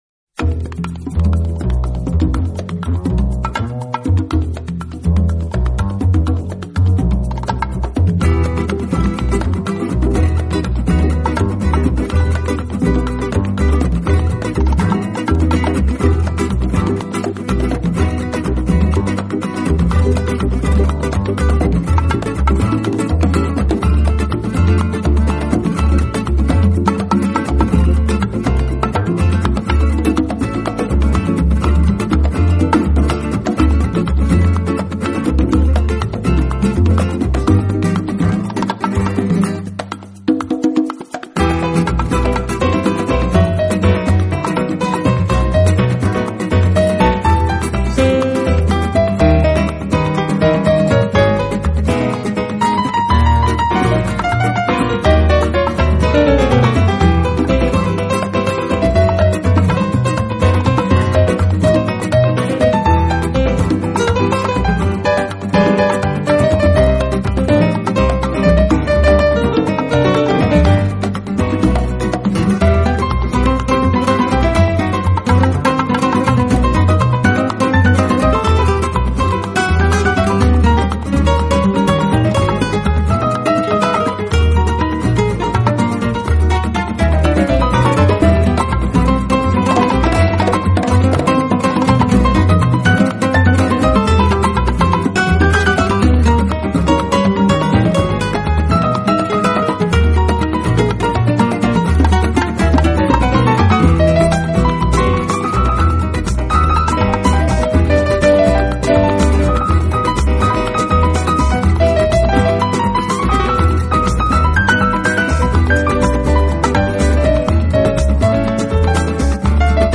Virtuoz zongorajatek es gitarszolok valtogatjak egymast.